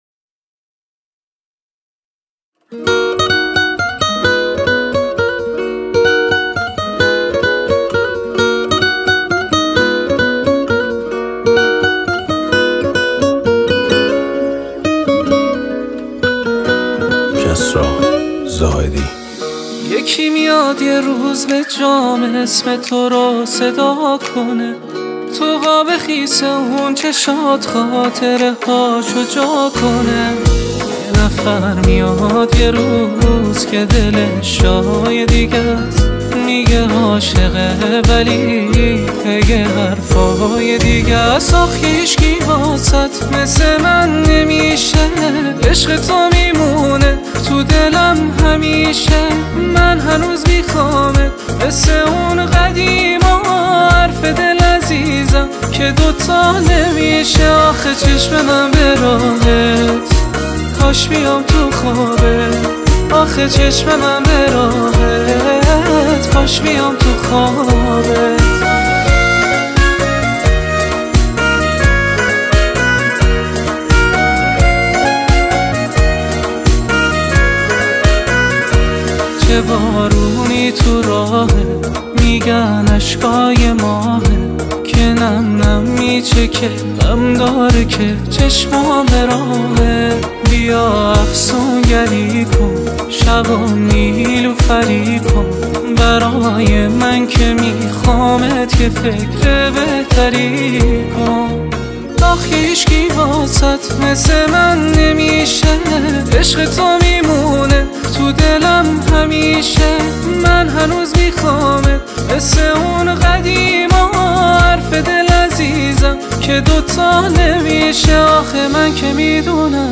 عاشقانه
عاشقانه فارسی